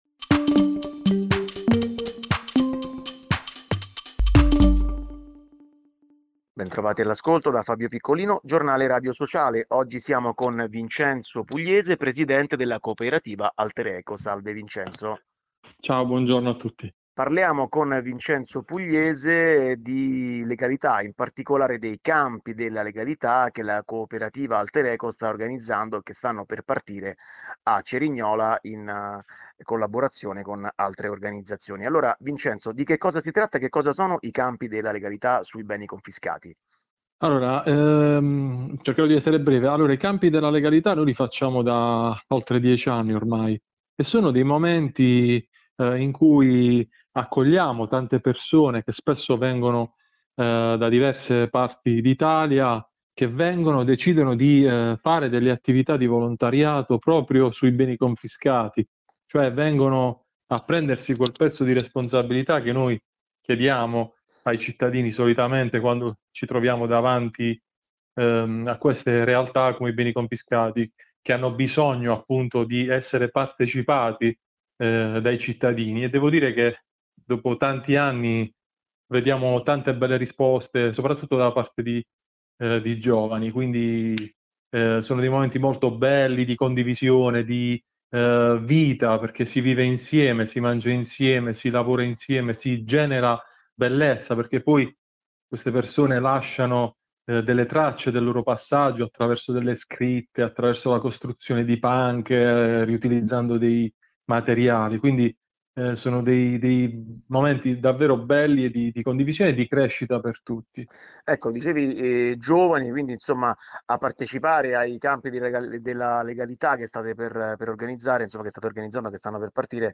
Mafie, far respirare ai giovani “il fresco profumo della libertà”. Intervista